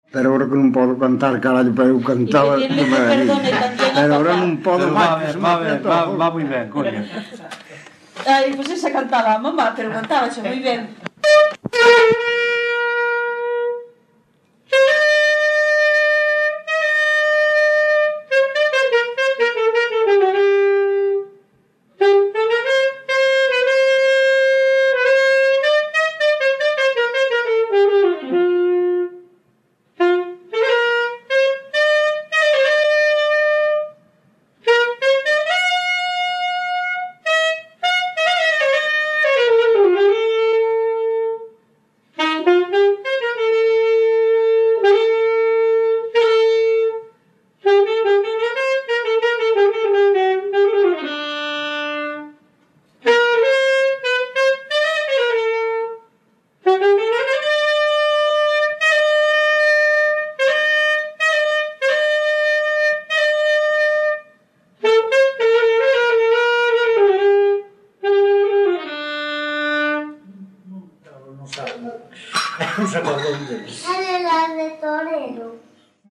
Peza de saxofón 5
Palabras chave: instrumental
Soporte orixinal: Casete
Xénero: Pasodobre
Instrumentos: Saxofón